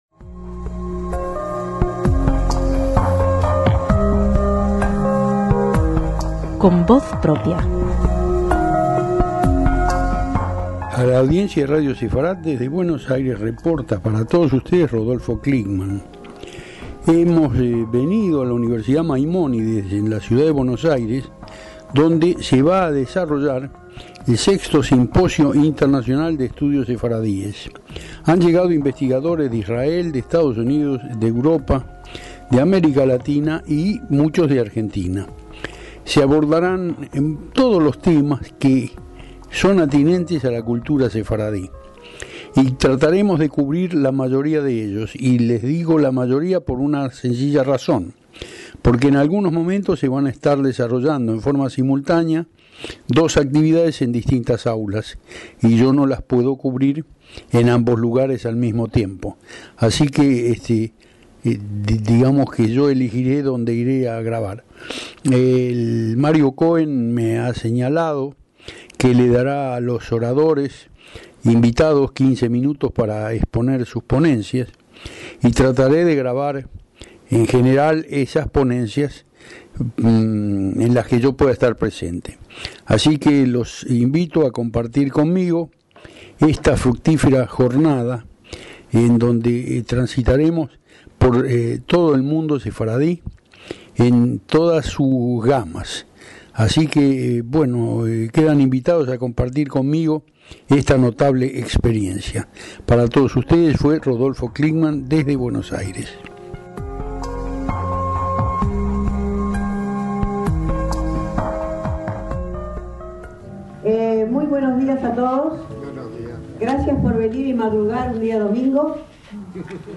Desde Argentina nuestro colaborador nos envía la grabación de algunas de las conferencias que tuvieron lugar en el VI Simposio Internacional de Estudios Sefardíes.